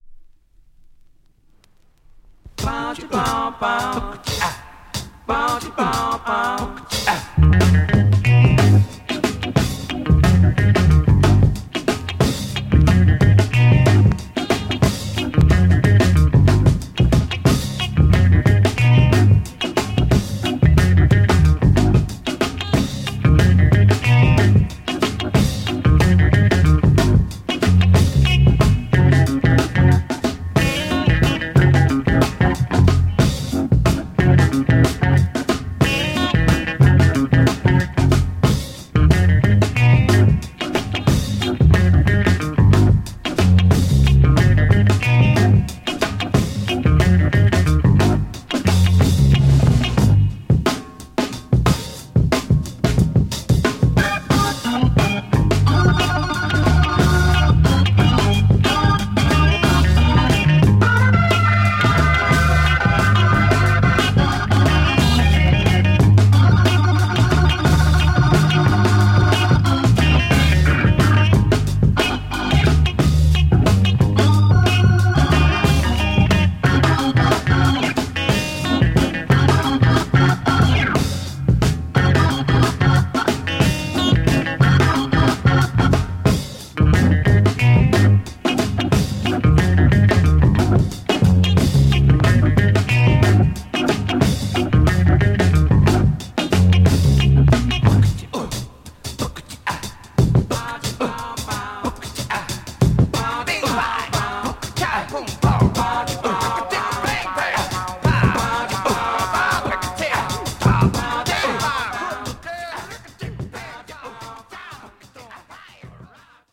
Classic US Funk break EP
Classic funk break